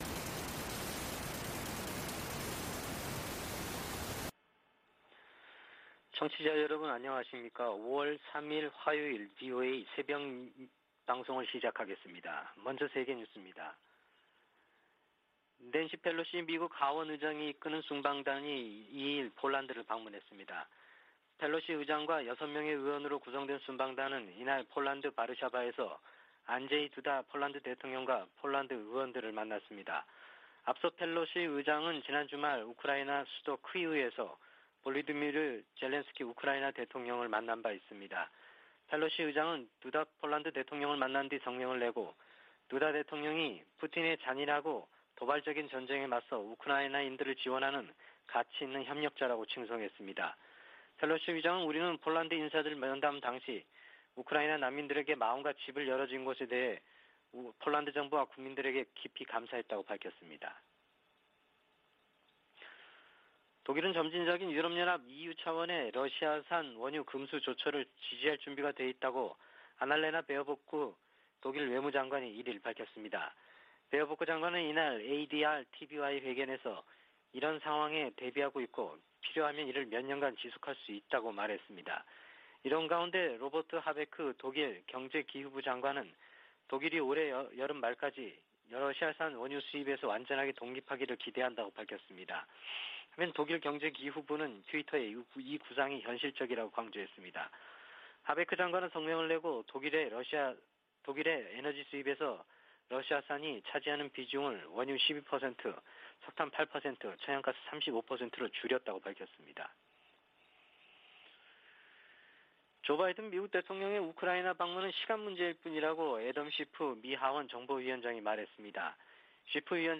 VOA 한국어 '출발 뉴스 쇼', 2022년 5월 3일 방송입니다. 미 국무부가 핵실험 준비 동향이 포착된 북한에 대해 역내에 심각한 불안정을 초래한다고 지적하고 대화를 촉구했습니다. 북한 풍계리 핵실험장 3번 갱도 내부와 새 입구 주변에서 공사가 활발히 진행 중이라는 위성사진 분석이 나왔습니다. 빌 해거티 미 상원의원은 조 바이든 대통령의 한일 순방이 인도태평양 지역 적국들에 중요한 신호를 보낼 것이라고 밝혔습니다.